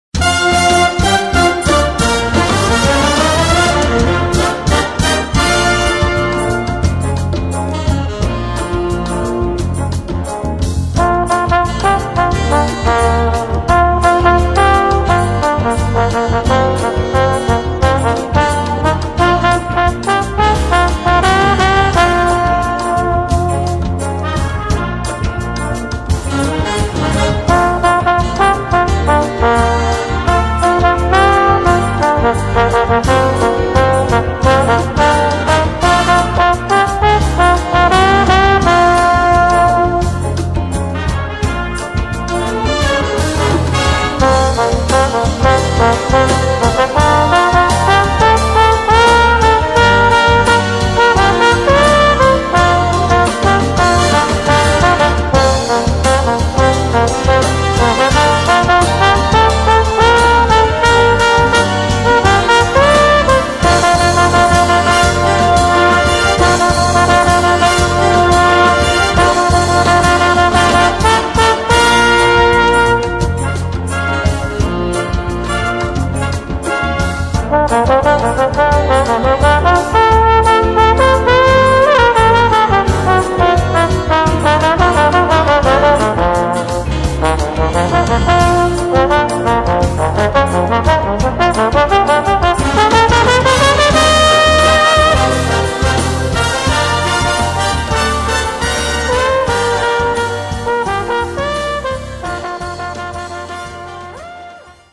Voicing: Trombone and Brass Band